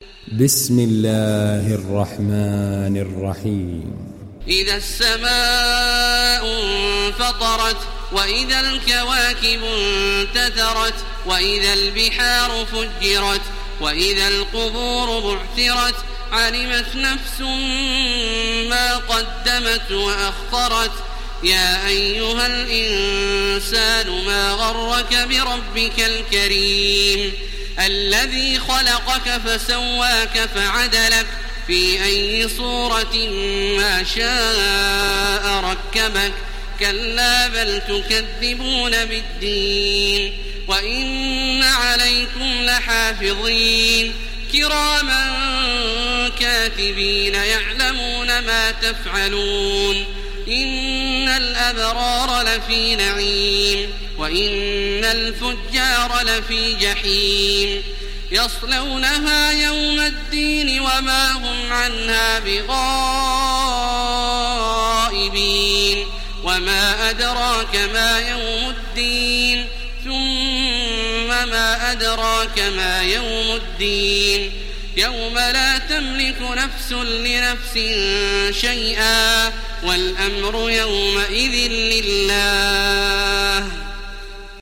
ডাউনলোড সূরা আল-ইনফিতার Taraweeh Makkah 1430